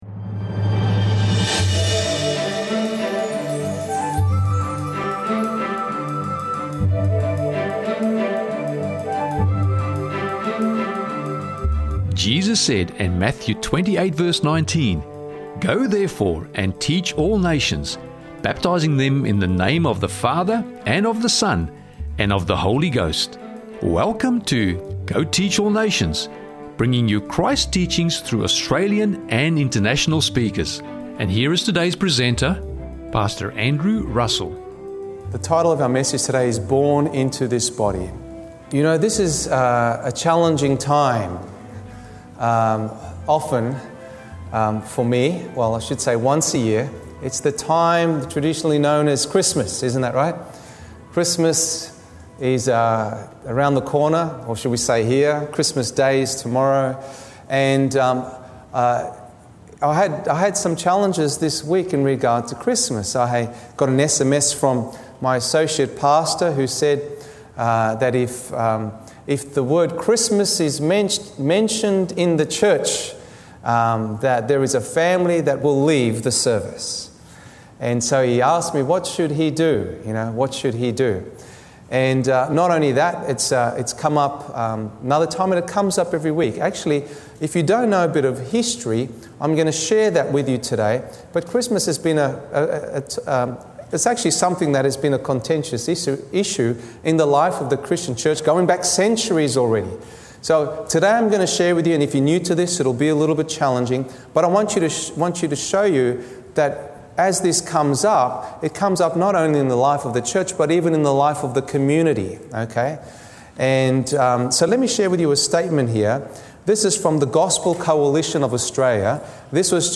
This message was made available by the Dundas Seventh-day Adventist church.